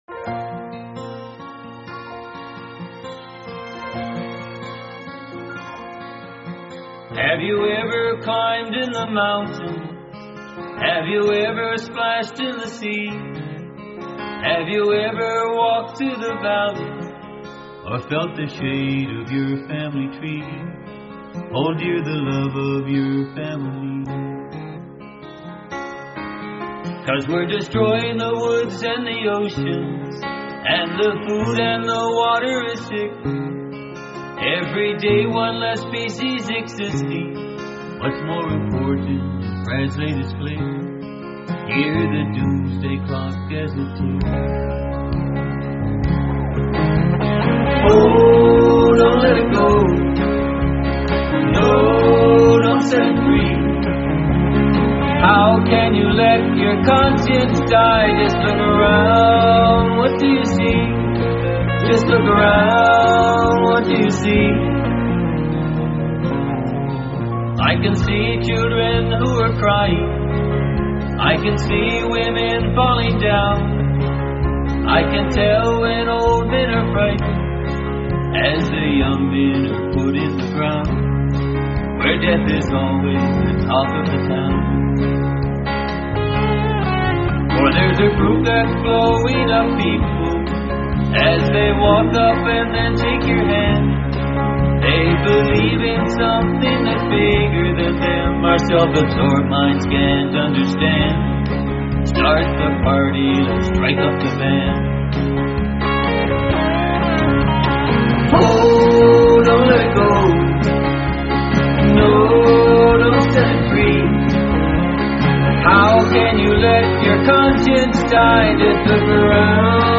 (Genre: Country)